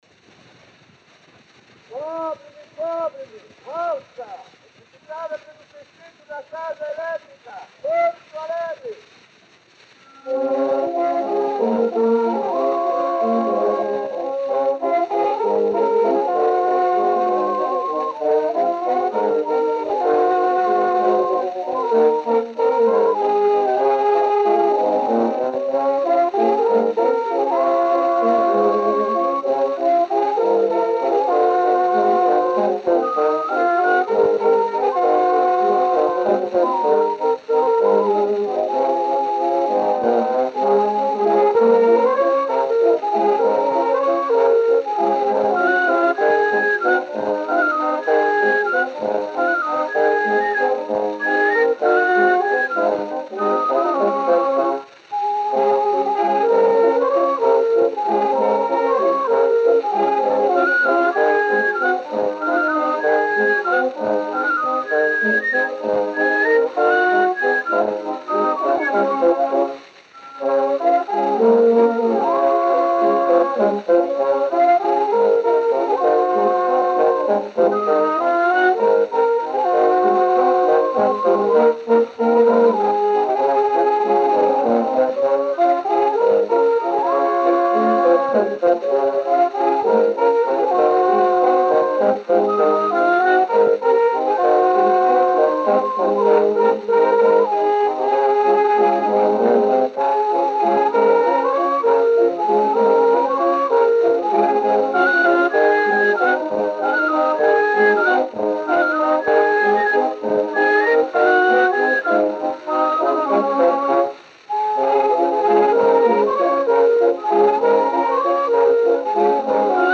Disco de 78 rotações, também chamado "78 rpm", gravado em apenas um dos lados e com rótulo "tricolor".
A performance da gravação foi executada por "Sextetto da Casa" e o gênero musical foi descrito como "Valsa".
Gênero: Valsa.